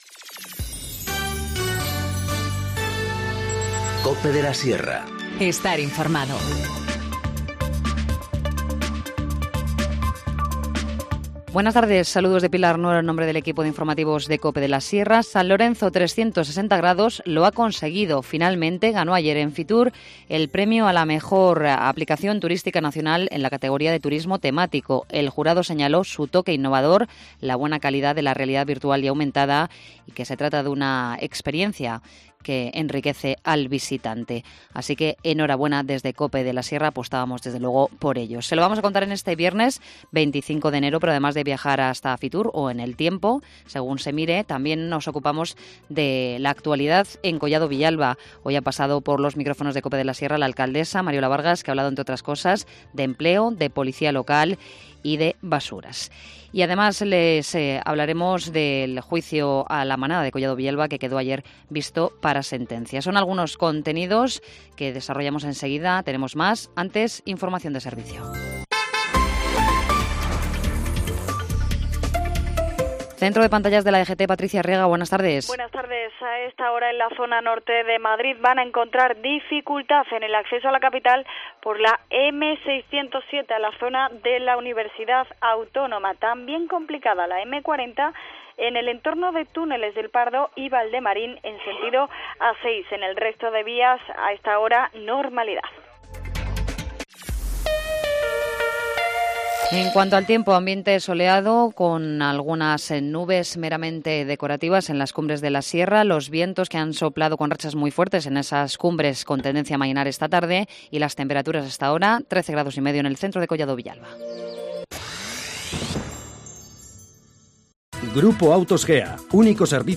Informativo Mediodía 25 enero- 14:20h